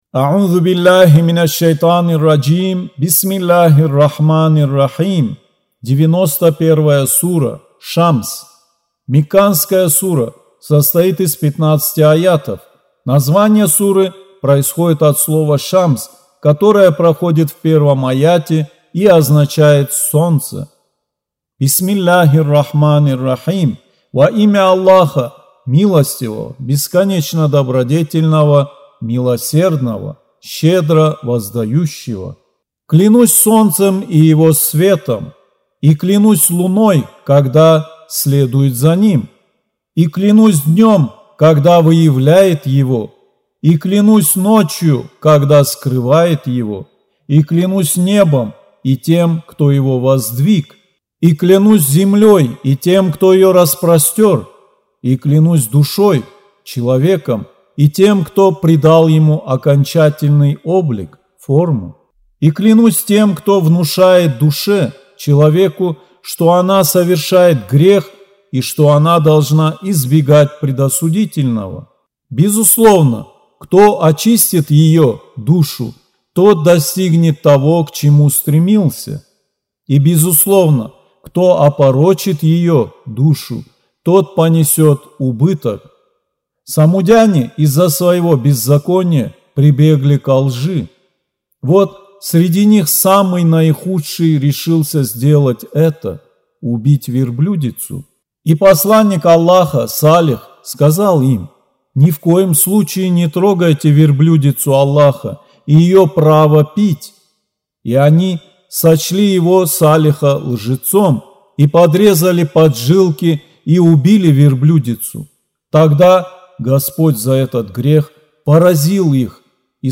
Аудио Коран 91.